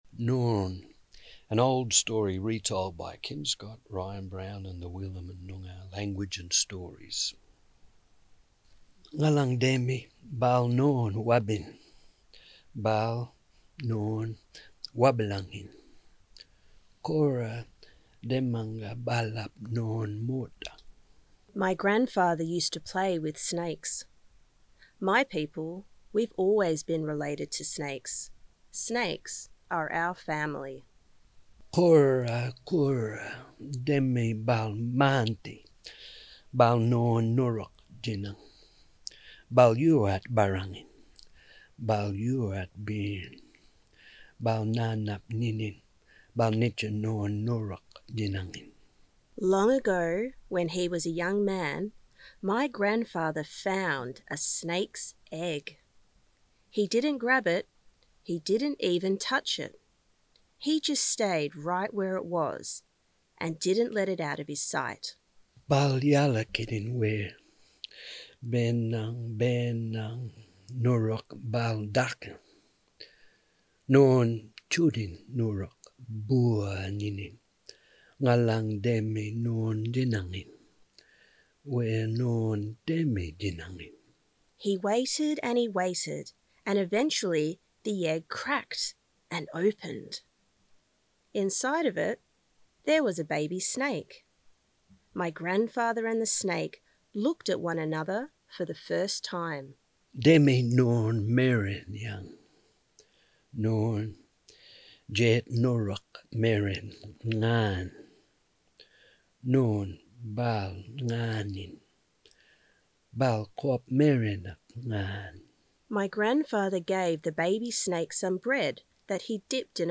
Reading of Noorn with captions
NoornReadingEdit.mp3